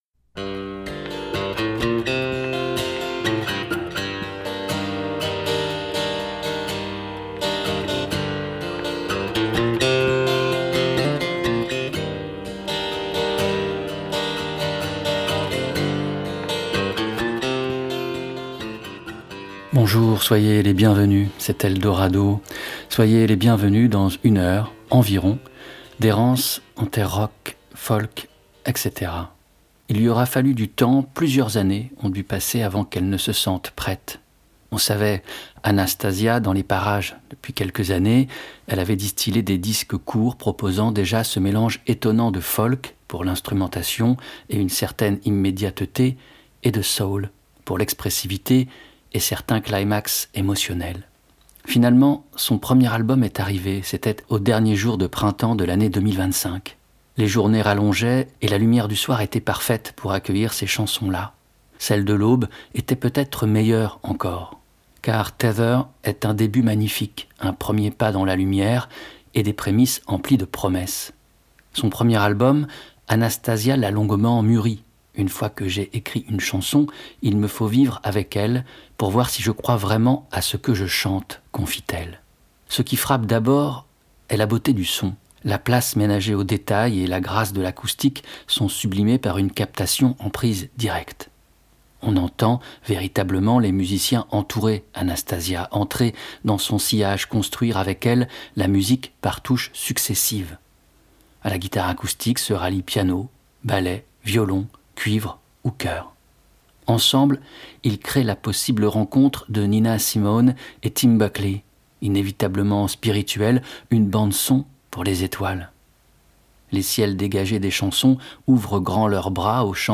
Émission de radio - Errances en terres folk, rock, etc - En écoute sur 15 radios francophones … continue reading 150 епізодів # Musique # © Eldorado 2014 # Folk # Rock